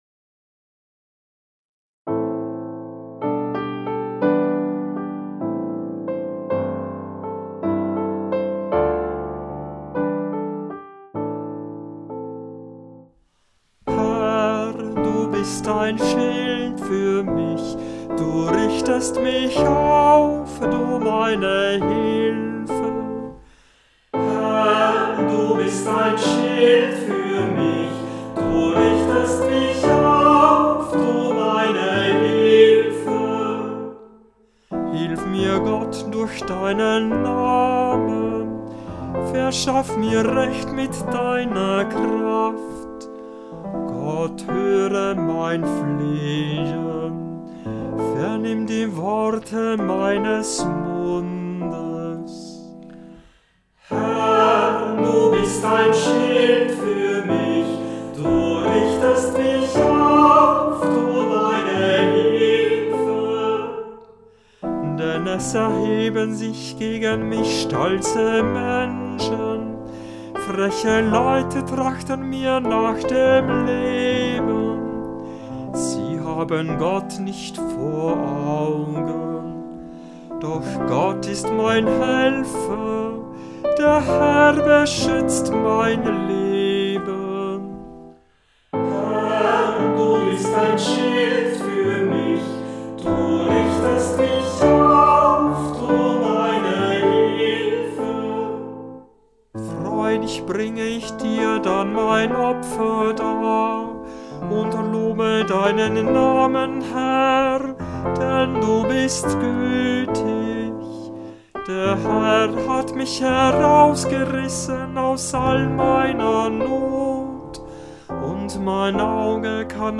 Hörbeispiele aus Kantorenbüchern und eine Auswahl aus dem Gurker Psalter